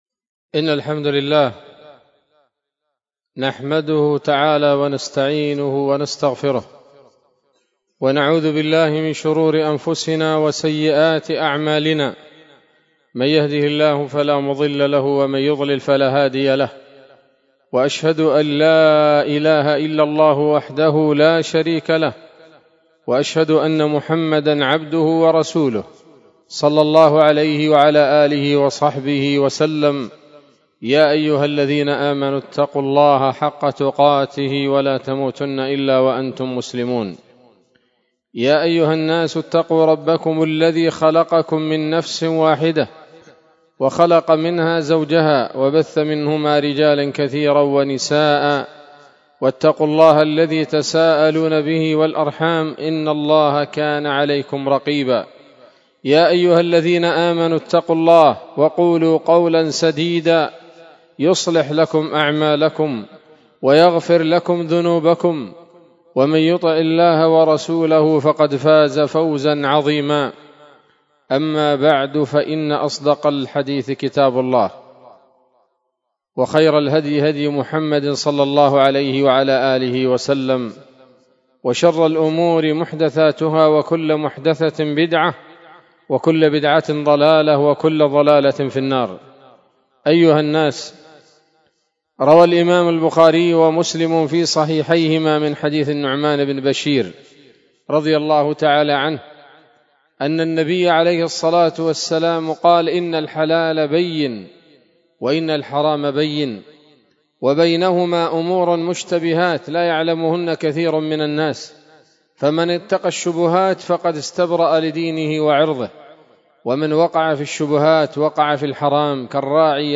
خطبة-جمعة-بعنوان-أمراض-القلوب.mp3